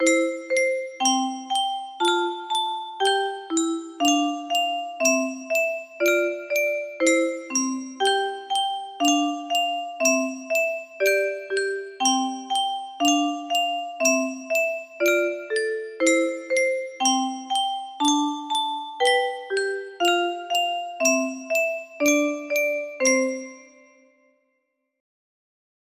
작은별 music box melody